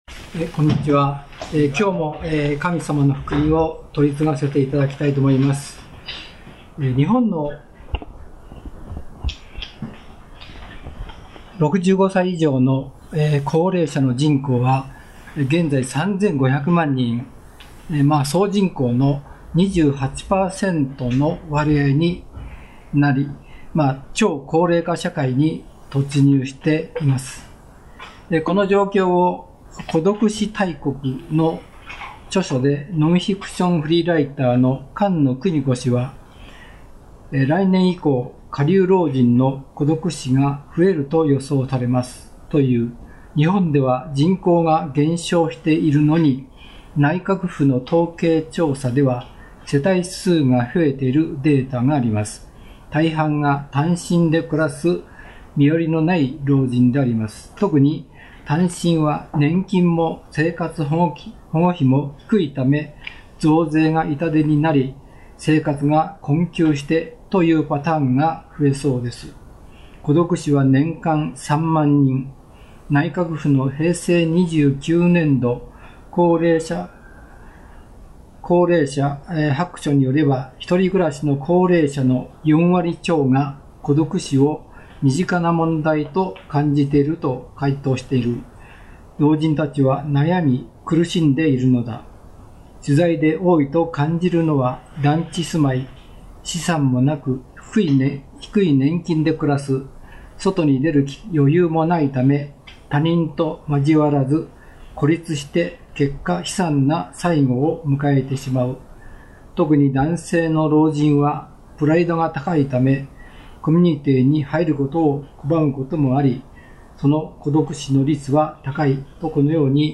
聖書メッセージ 隣人を自分自身と同じように愛しなさい